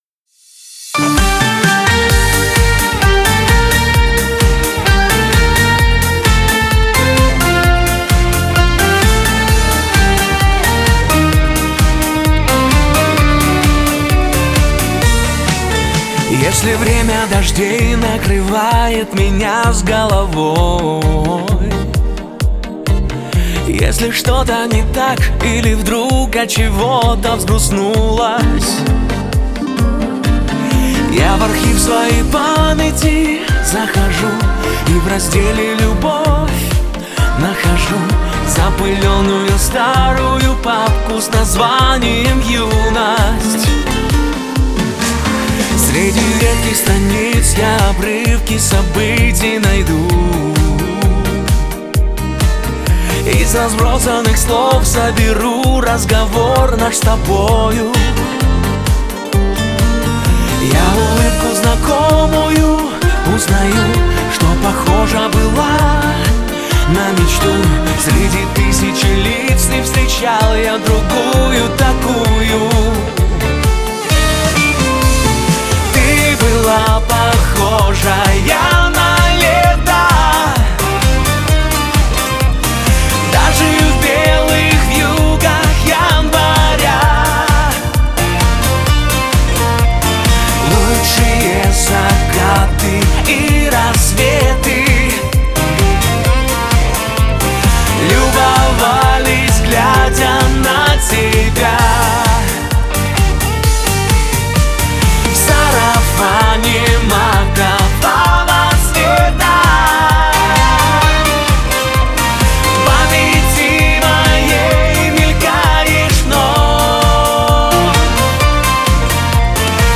Шансон
Вокал
Гитара
Саксофон
Клавишные
Скрипка
Бас-гитара
Ударные